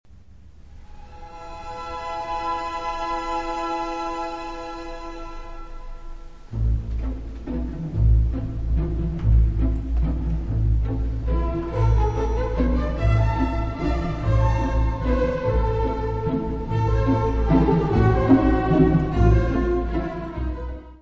Herbert Von Karajan; Berliner Philharmoniker
Music Category/Genre:  Classical Music